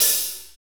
Index of /90_sSampleCDs/Northstar - Drumscapes Roland/DRM_Hip-Hop_Rap/HAT_H_H Hats x
HAT R B LH0N.wav